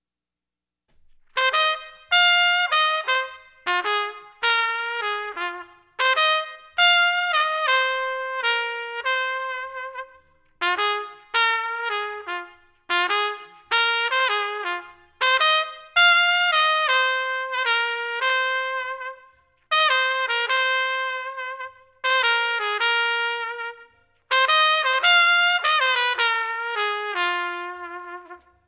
In our first set of results, we used some trumpet and guitar lesson recordings as the example data.
Trumpet Lesson (2)
trumpet16.wav